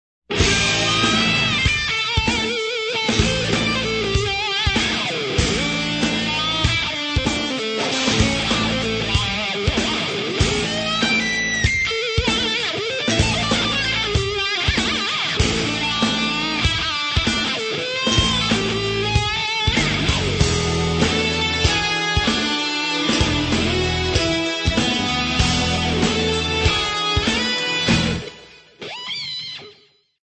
Drums
Recorded at The Mothership